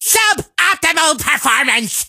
carl_death_vo_03.ogg